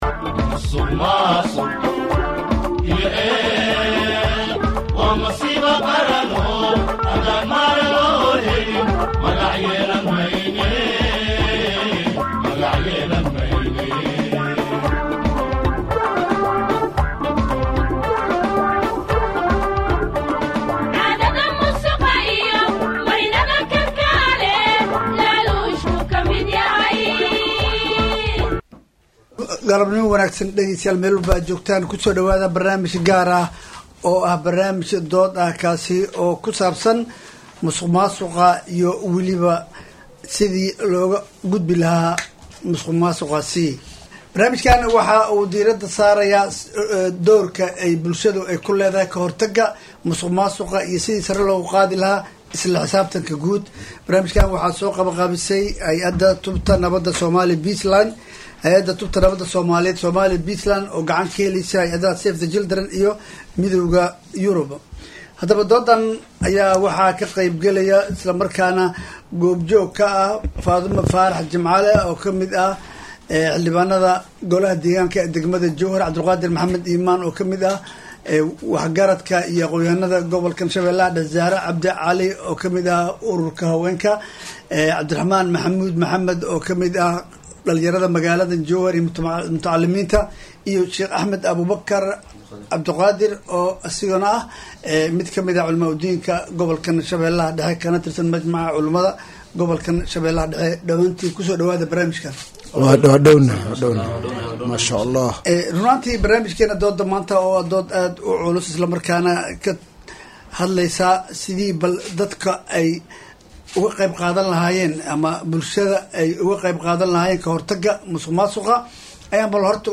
Halkaan ka Dhageyso Barnaamij Dood Wadaag ah oo ku saabsan Ka Hortagga Musuqmaasuqa iyo Doorka Bulshada barnaamijka waxaa taabagelisay Hay’ada Tubta Nabada Soomaaliyeed iyadoo gacan ka heleysa save the children iyo European union waxaana baahinayay Radiojowhar